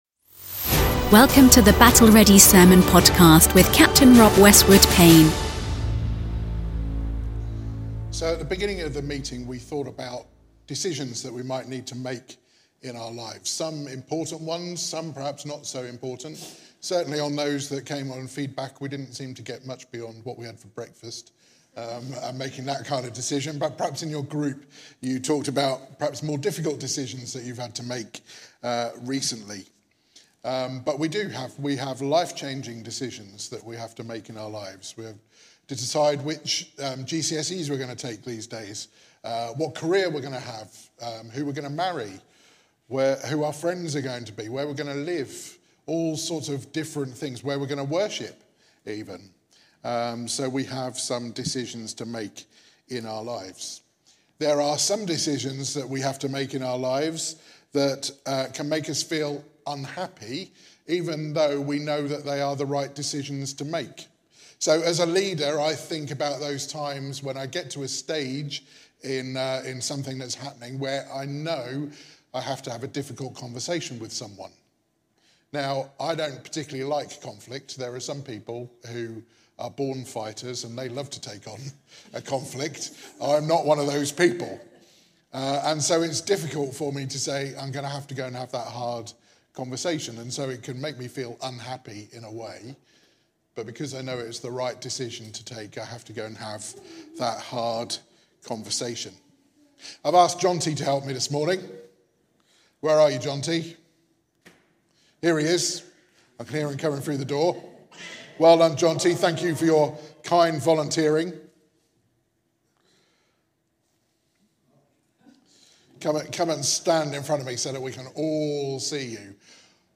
Battle Ready Sermons Jesus : Is He Worth It?